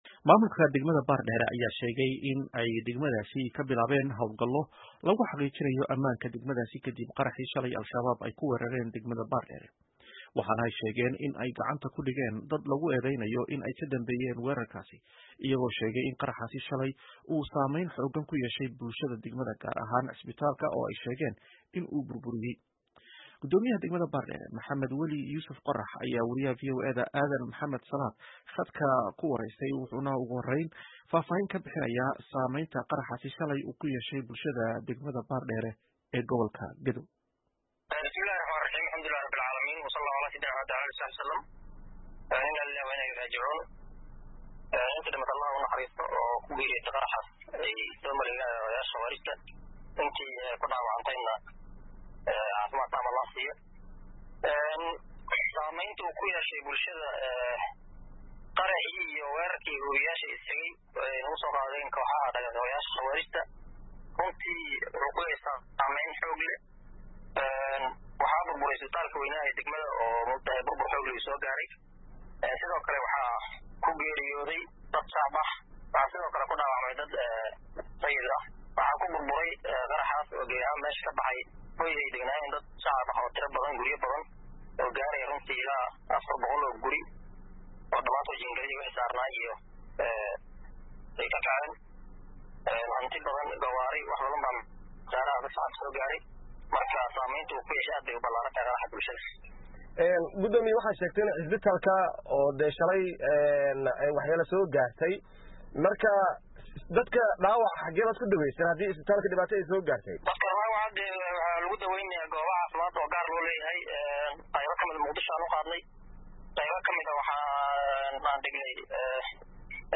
Guddoomiyaha degmada Baardheere Maxamed-Weli Yuusuf Qorrax oo la hadlay Idaacadda VOA ayaa ka warbixiyey saameynta uu qaraxaasi ku yeeshay dadka reer Baardheere.